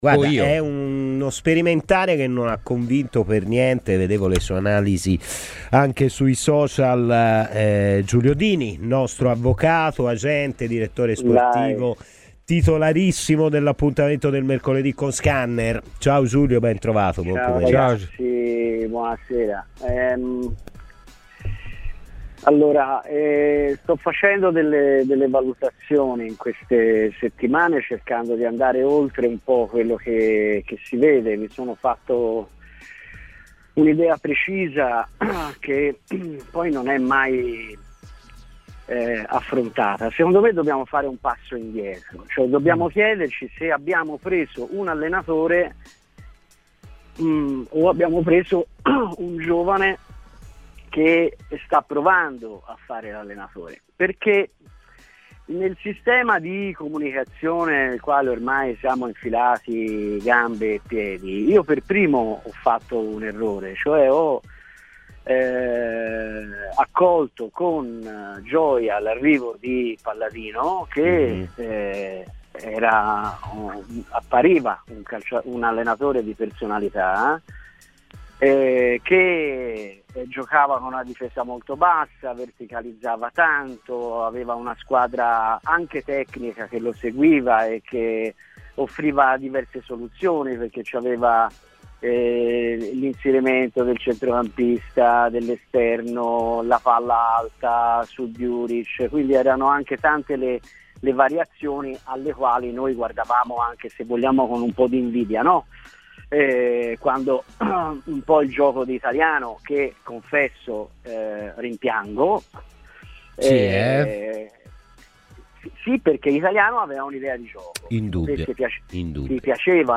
in diretta su Radio Firenzeviola